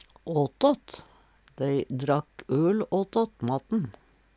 åtåt - Numedalsmål (en-US)